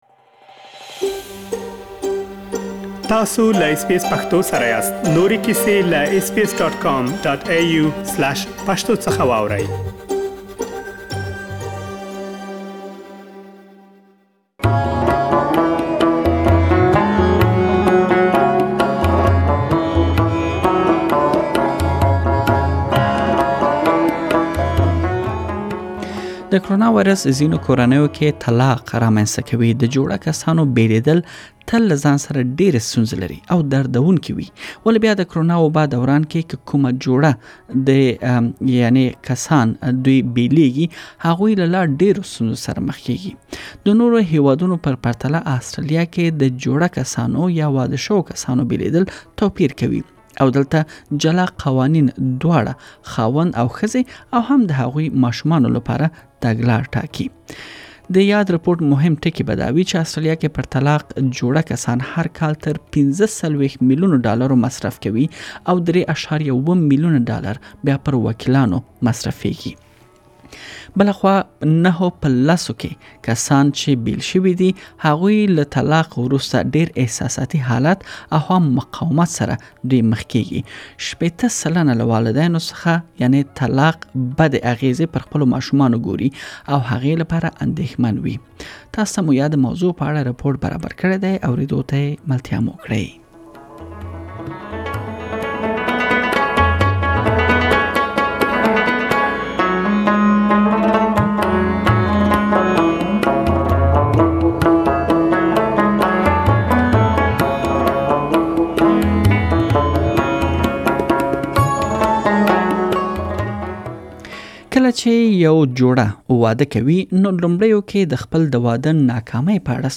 تاسو ته مو نوې څيړنې موندنې او کورنيو خدمتو چارواکو غږونه راخيستي چې دا ټول پدې تيار شوي رپوت کې اوريدلی شئ.